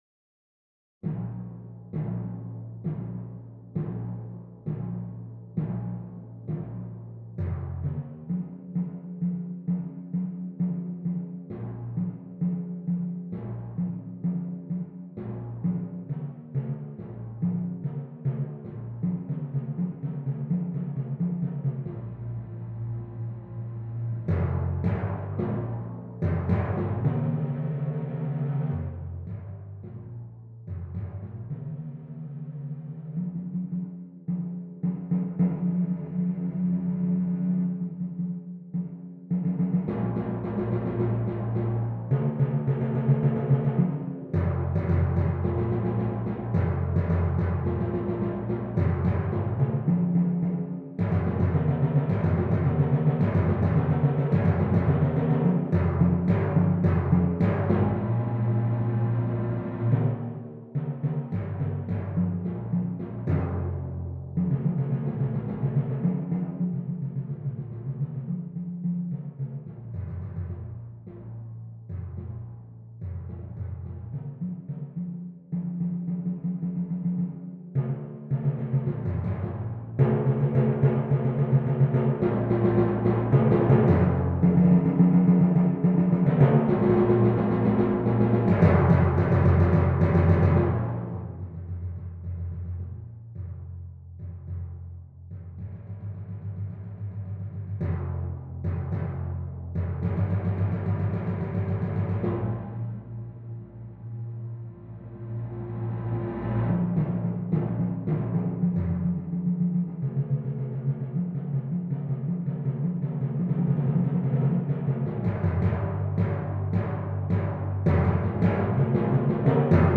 Percussion Ensemble
six timpani